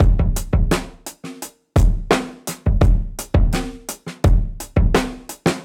Index of /musicradar/dusty-funk-samples/Beats/85bpm
DF_BeatB_85-01.wav